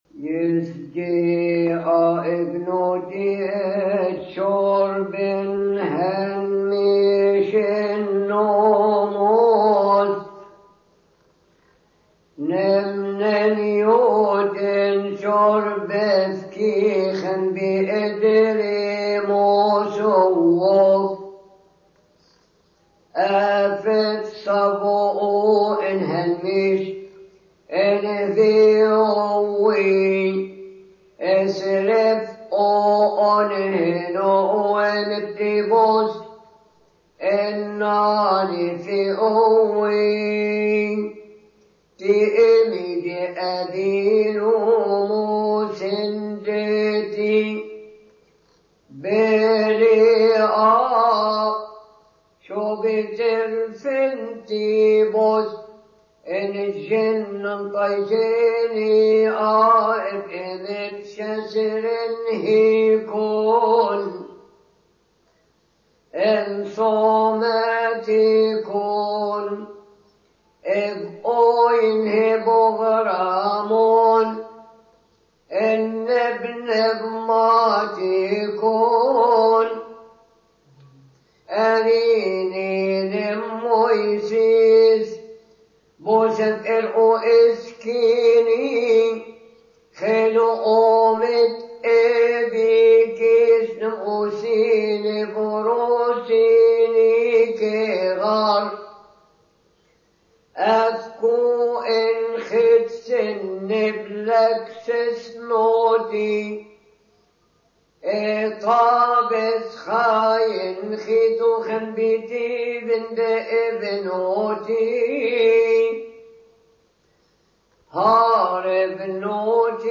ثيؤطوكية يوم الاحد القطعة الاولى (الكيهكي)